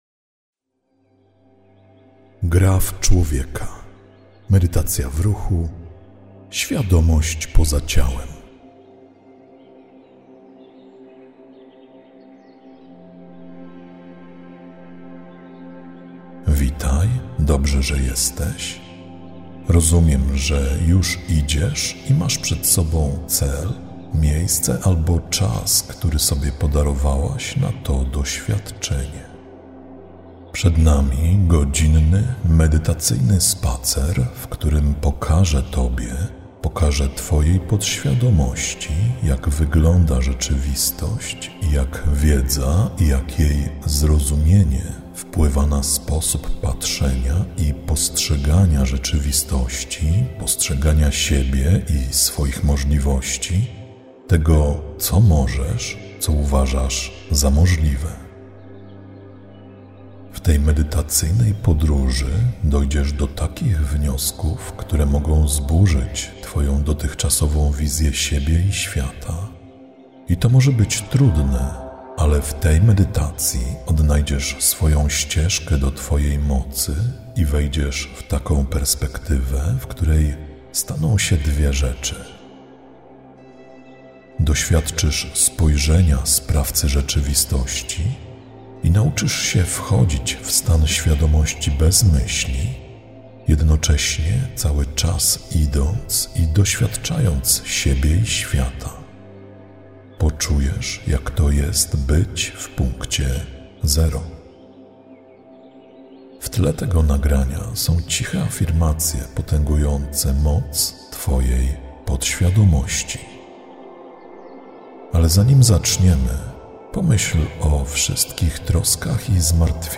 Zawiera lektora: Tak
Spacerujesz słuchając mojego głosu.